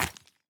footsteps
ore-03.ogg